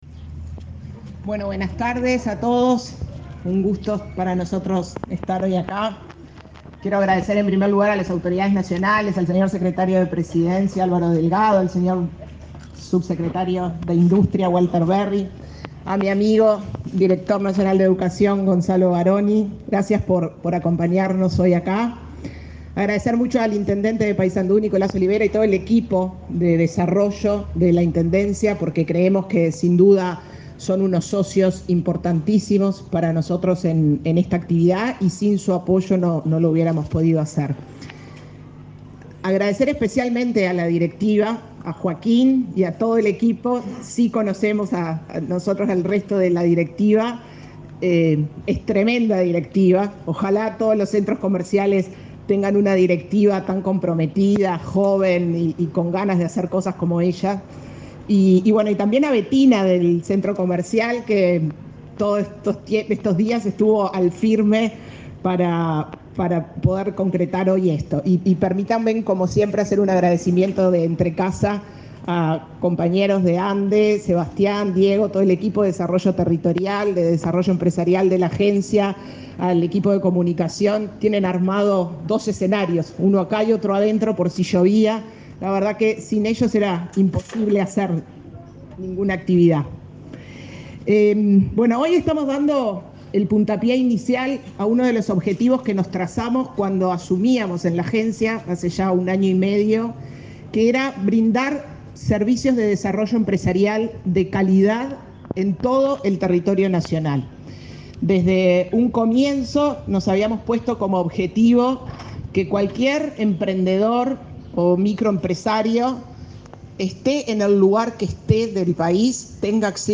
Conferencia de prensa de autoridades en la inauguración del Centro Pyme en Paysandú
Con la participación del secretario de Presidencia, Álvaro Delgado; la presidenta de la Agencia Nacional de Desarrollo, Cármen Sánchez, y el